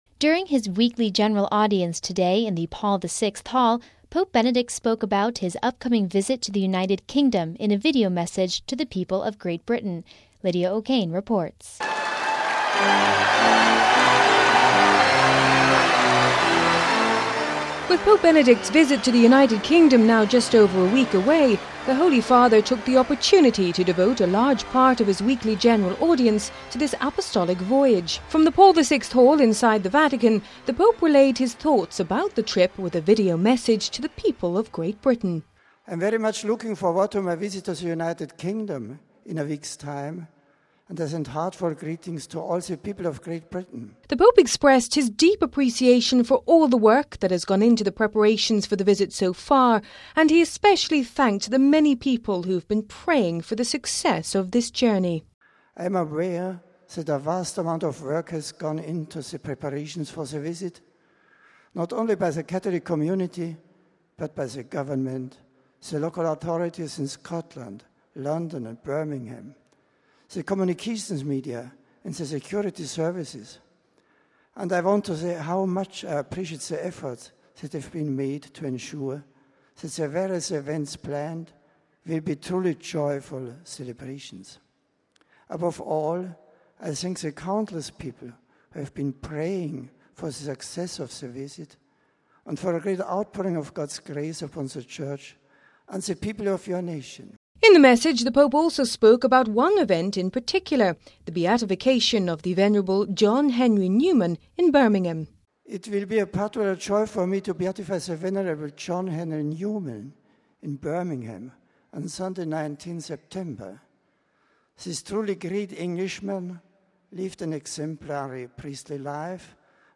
Pope Benedict today during his Weekly General Audience today in the Paul the VI Hall spoke about his upcoming visit to the United Kingdom in a video message to the people of Great Britain...
From the Paul the VI Hall inside the Vatican the Pope relayed his thoughts about the trip with a video message to the people of Great Britain.